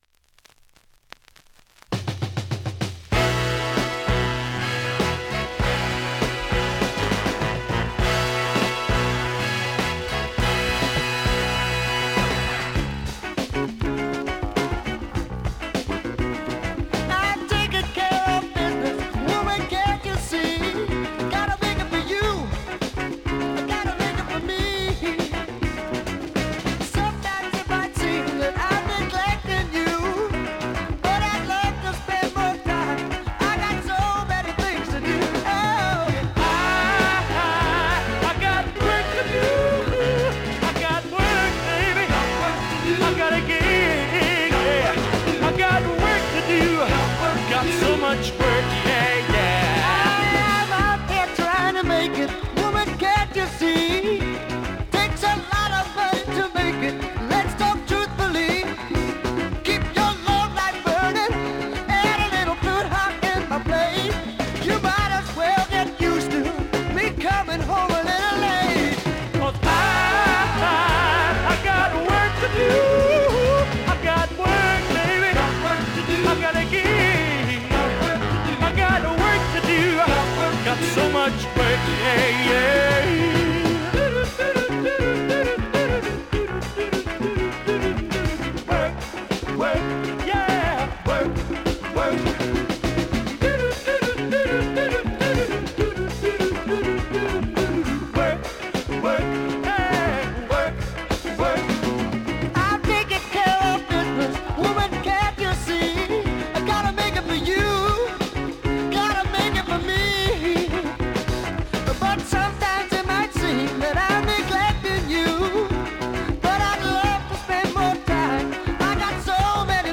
SOUL、FUNK、JAZZのオリジナルアナログ盤専門店
現物の試聴（両面すべて録音時間8分17秒）できます。